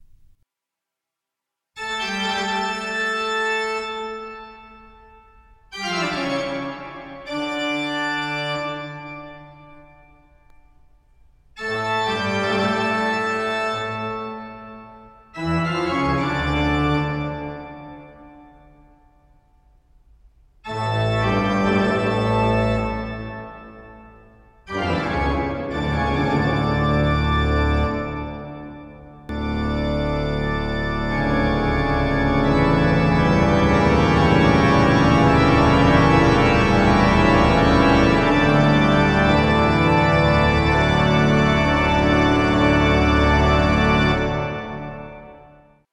organo.mp3